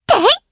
Aisha-Vox_Attack3_jp.wav